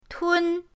tun1.mp3